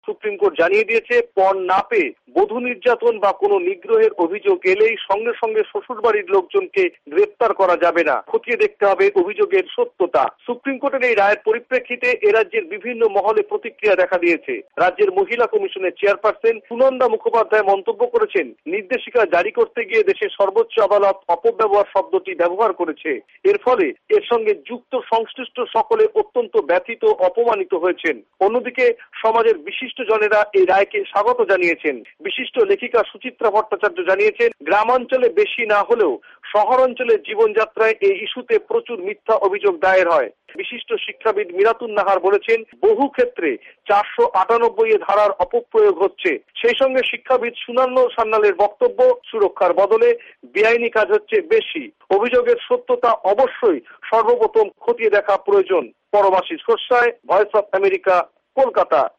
আমাদের কোলকাতা সংবাদদাতাদের প্রতিবেদন